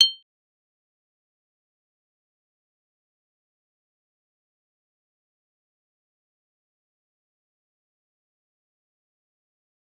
G_Kalimba-G8-mf.wav